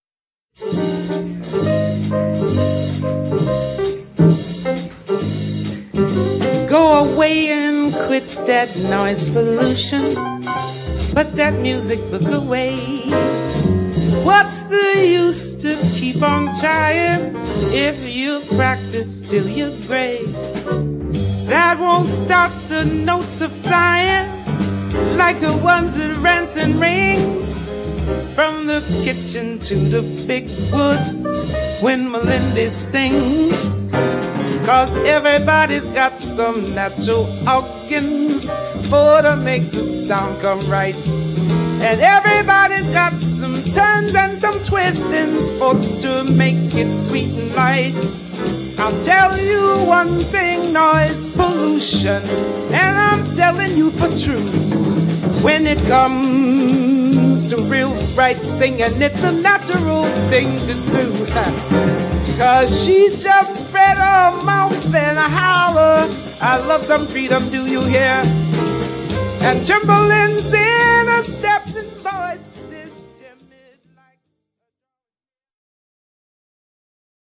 vocals
piano
bass
drums